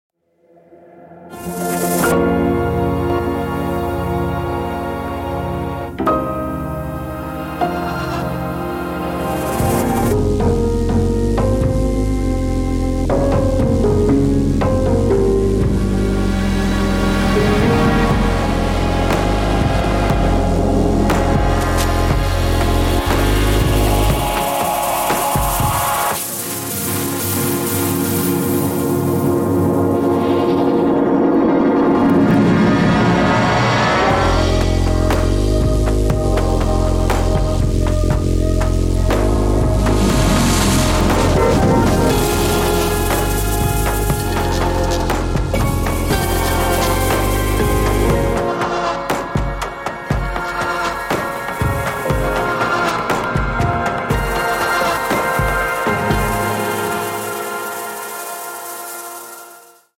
3. 合成器
- BPM 同步的 Drift 控制，可以添加美妙的类似磁带的杂乱和颤动效果。
- BPM 同步的 Tremolo 控制，可以添加运动和跳动效果。
- 50 种定制的脉冲响应混响，可以选择小、中、大、反向或弹簧等不同的空间类型和变化。